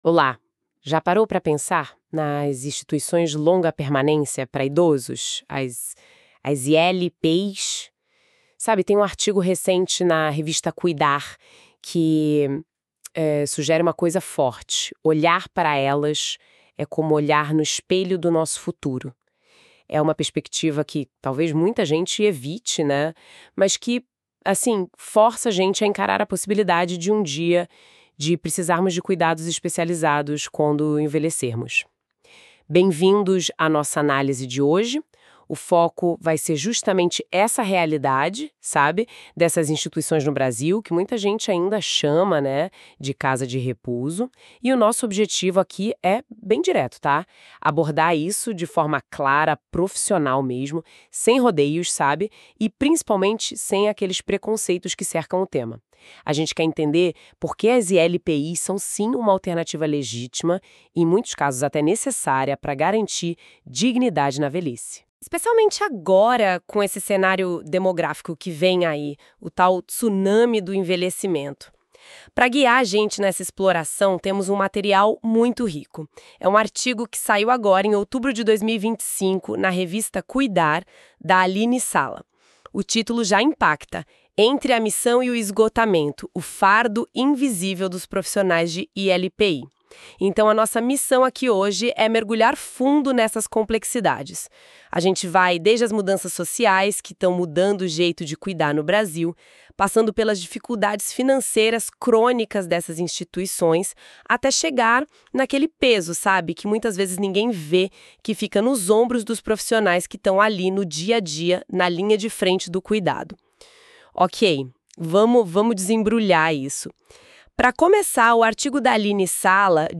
Se preferir ouça uma discussão sobre o artigo aqui: